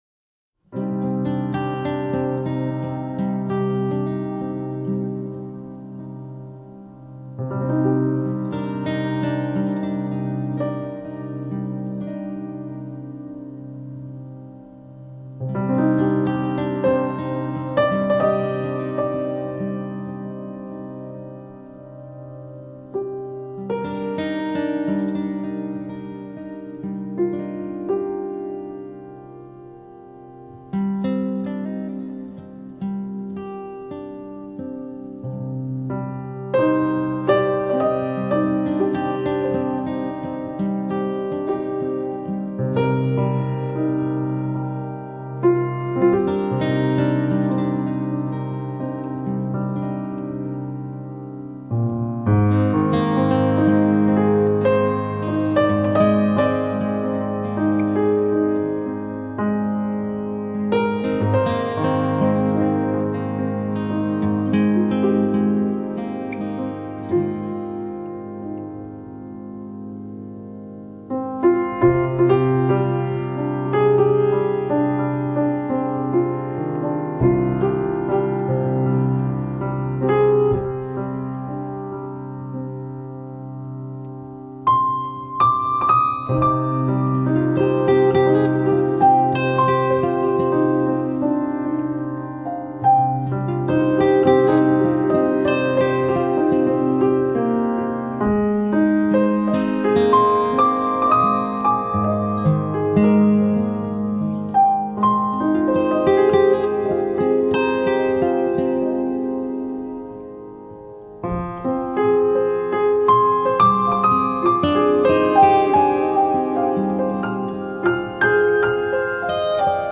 音乐类型：NewAge